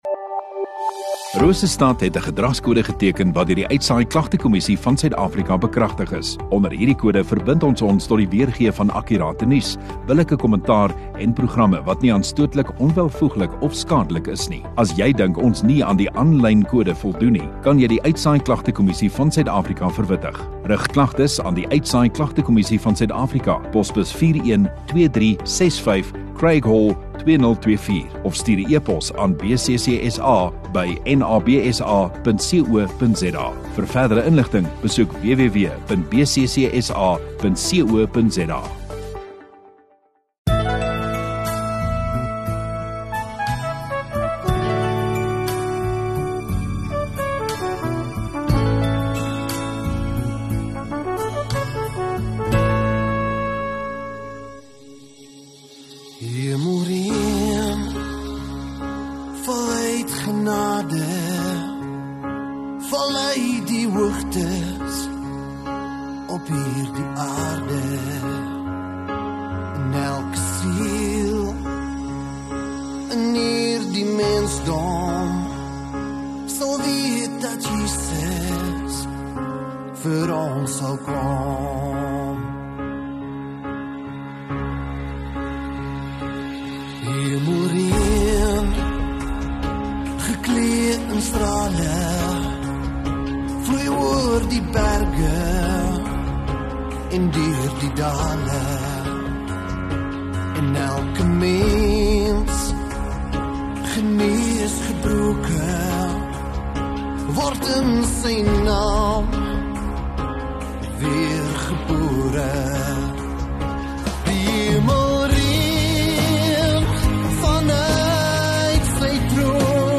20 Jul Saterdag Oggenddiens